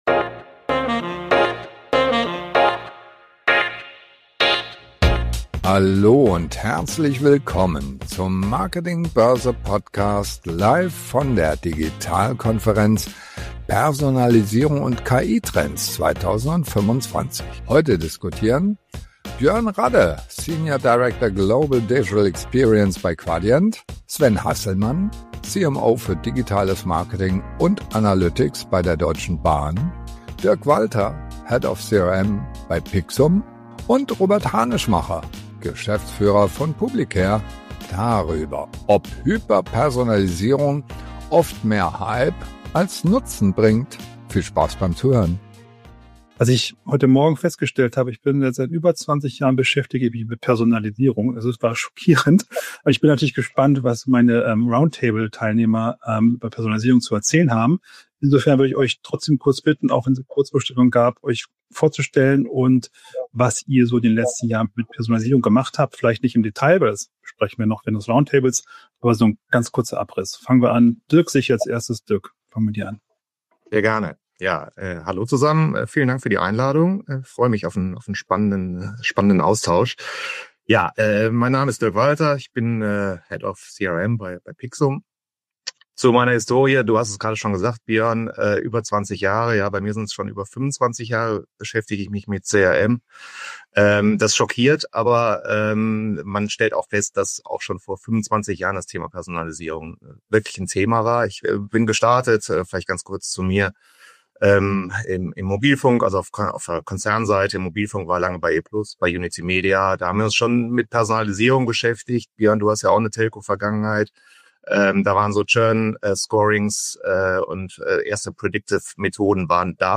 Personalisierung ist längst Standard – aber wie gelingt sie wirklich? Eine Diskussionsrunde über den Unterschied zwischen Hype und Realität, über Datensilo und künstliche Intelligenz als Spielfeld-Veränderer.